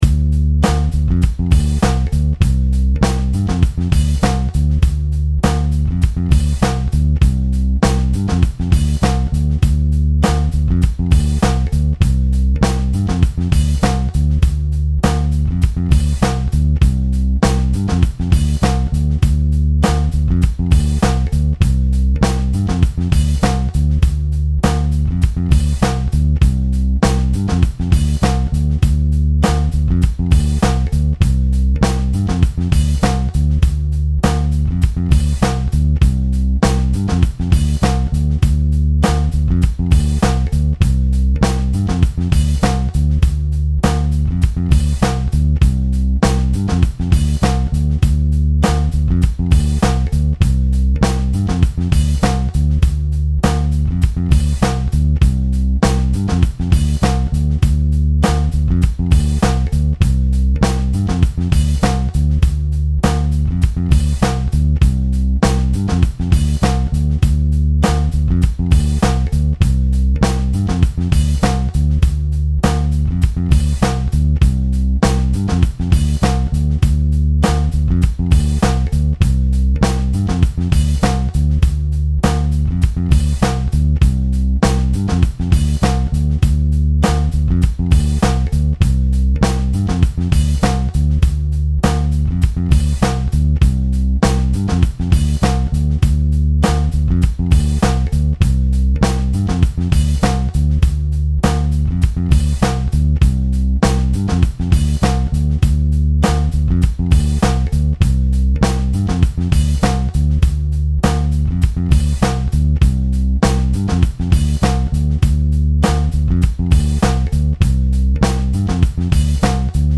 100 Rhythm Section